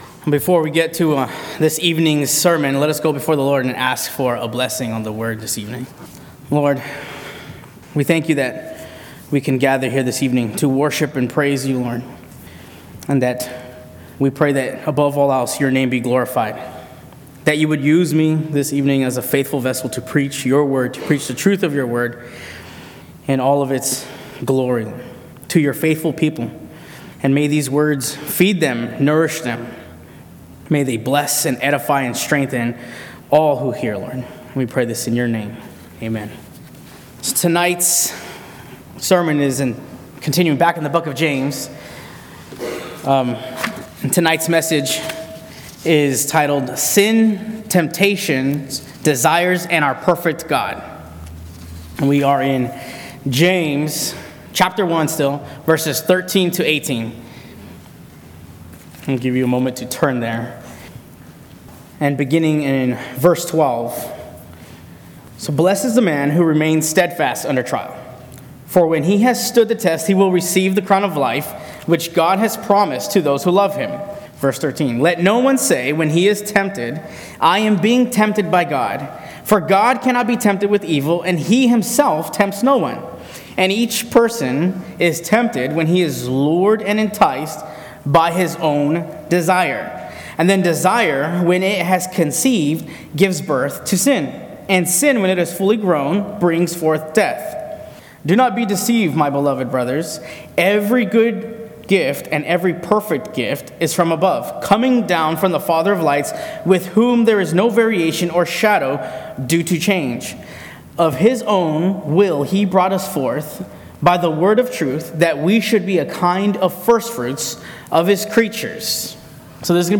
Temptation Series Various Sermons Book James Watch Listen Save In James 1:13-18, we learn that God is never to blame for our temptations during our trials, the true source of temptation, and how all good things come from God.